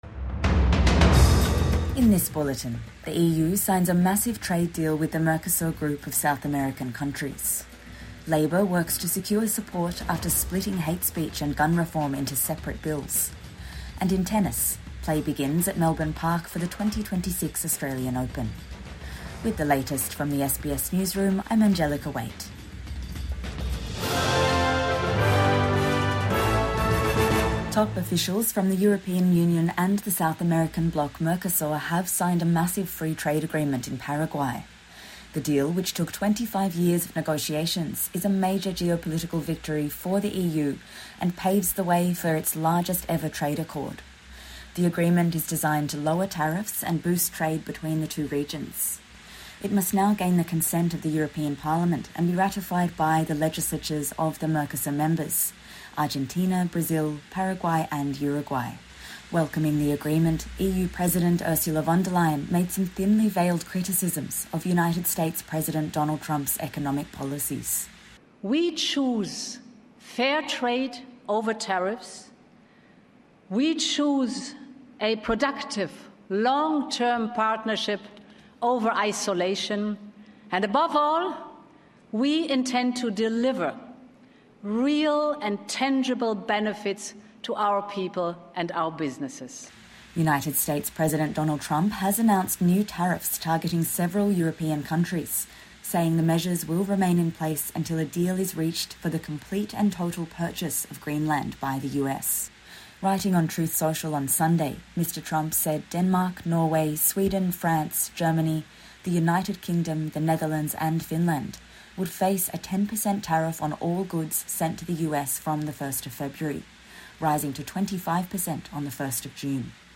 The EU signs trade deal with the Mercosur group | Morning News Bulletin 18 January 2026